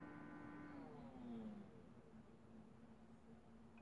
Tag: 环境 atmophere 记录